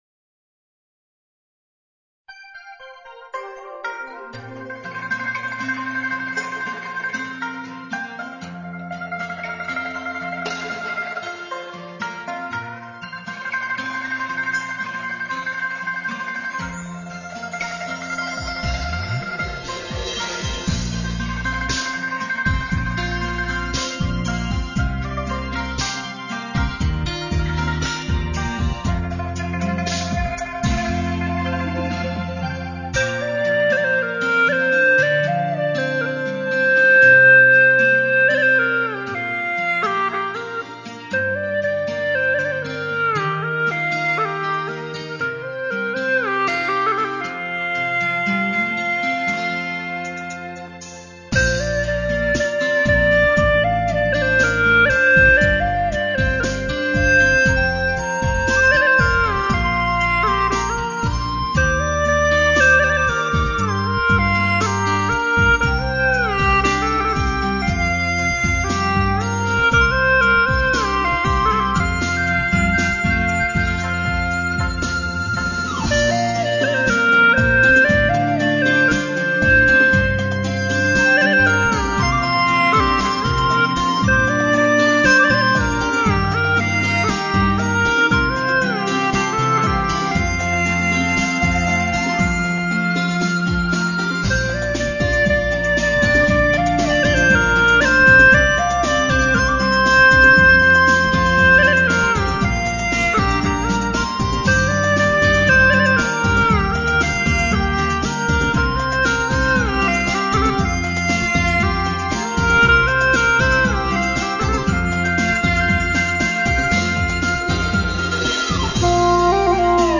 调式 : 降B 曲类 : 民族